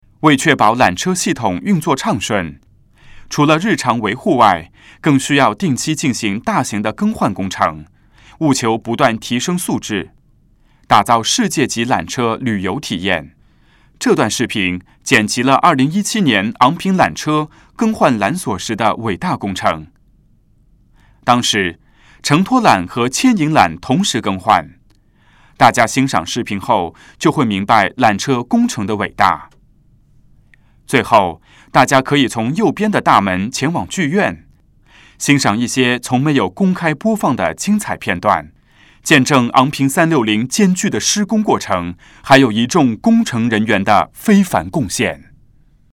缆车探知馆语音导赏 (普通话)